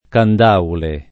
Candaule [ kand # ule ]